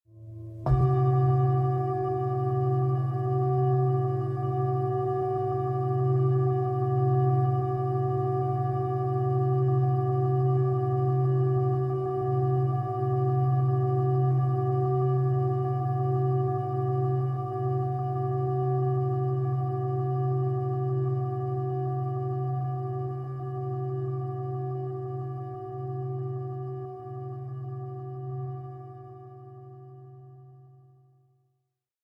gong.mp3